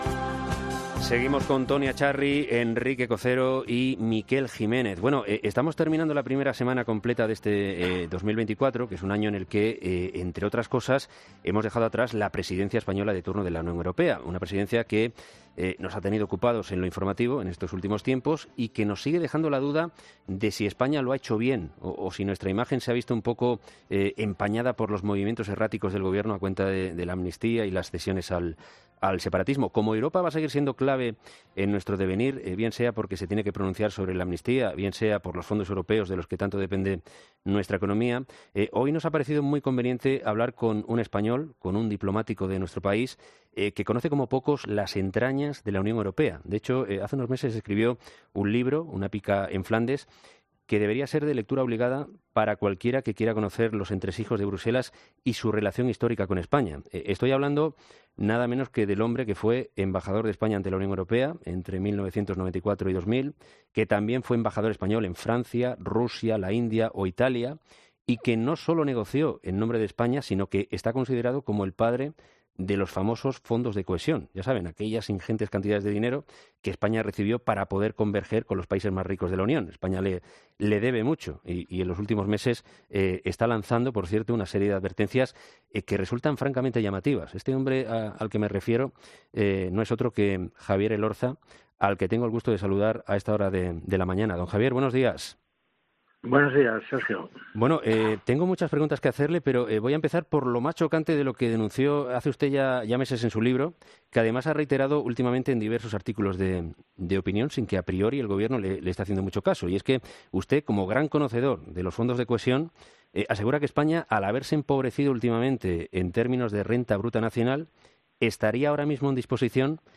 El hombre que fue embajador de España ante la UE pasa por los micrófonos de 'Herrera en COPE' para hablar sobre la situación de nuestro país en la UE y...